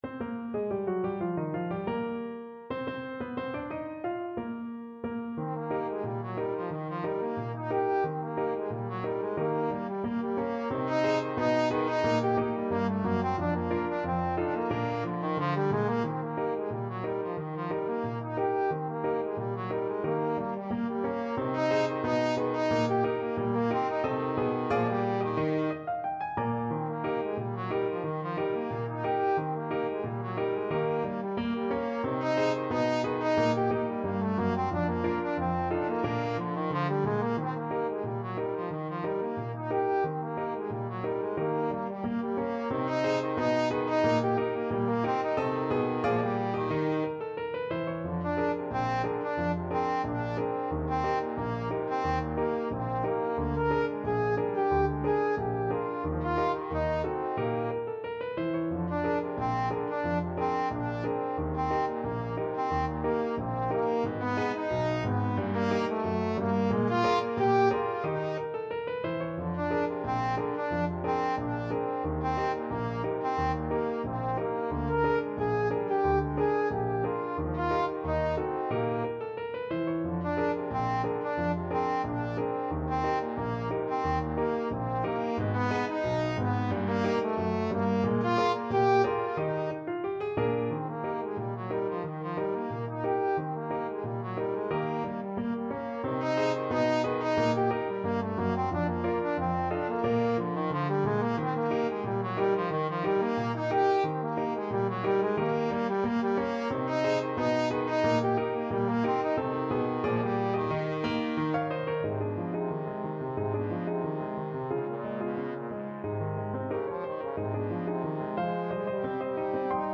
Trombone
Eb major (Sounding Pitch) (View more Eb major Music for Trombone )
2/4 (View more 2/4 Music)
Slow march tempo Slow March tempo. = 90
Jazz (View more Jazz Trombone Music)